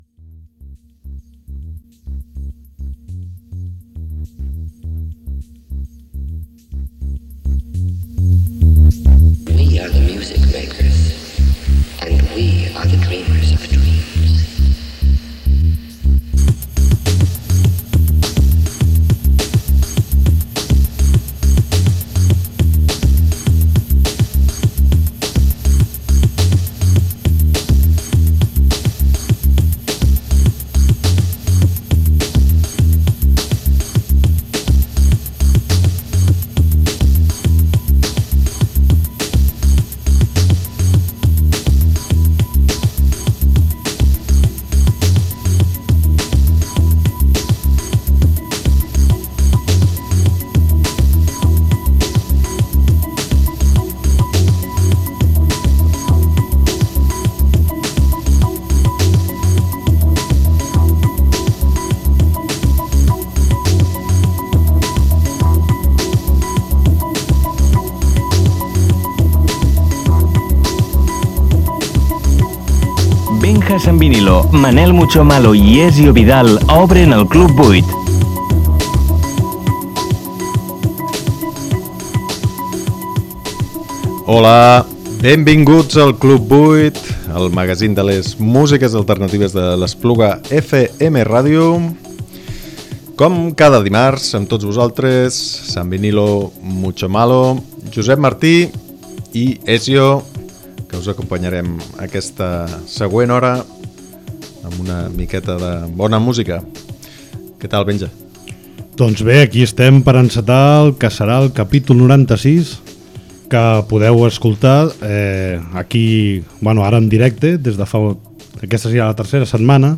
Aquest 14 de març us tornem a saludar en directe des de l’Estudi 2 de l’Espluga FM Ràdio. Hem preparat un grapat de cançons, algunes de les quals potser us sonaran de nou, per compartir una hora de desconnexió musical. Com de costum, parlarem dels artistes, les efemèrides i acabarem amb reflexions i alguna versió.